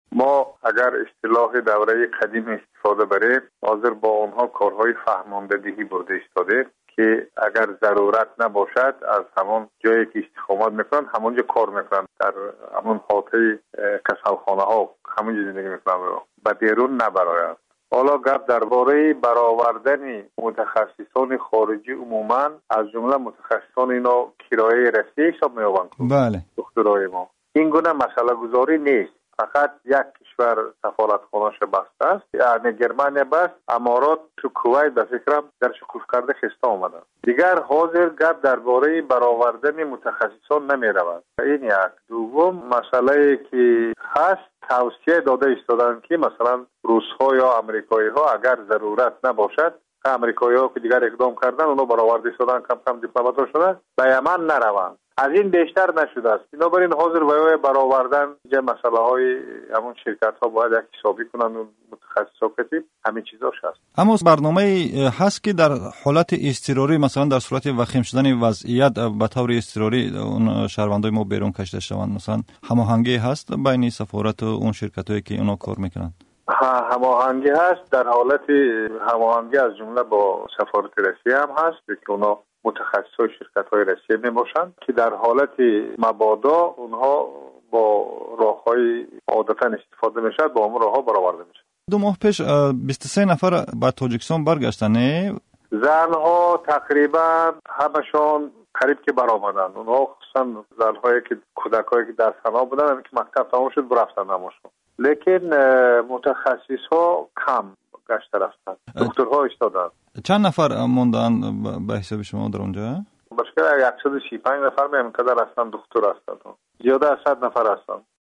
Гуфтугӯ бо сафири Тоҷикистон дар Арабистони Саудӣ